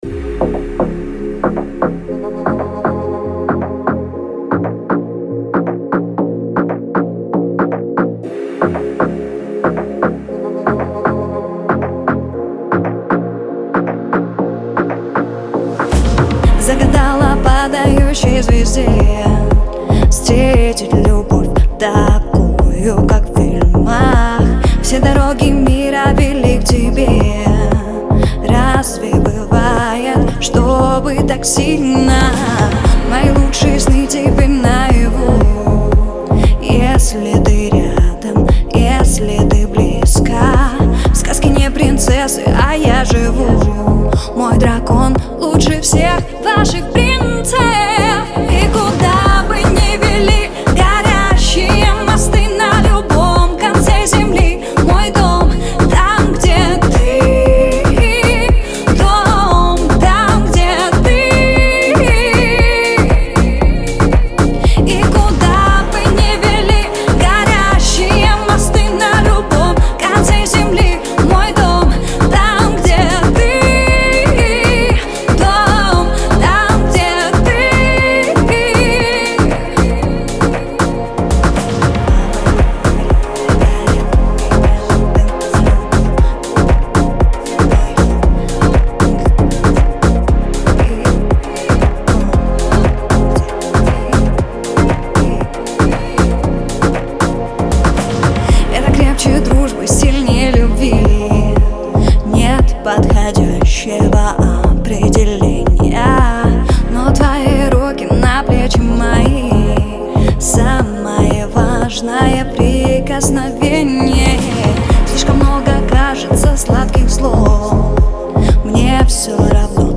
это трогательная песня в жанре поп с элементами фолка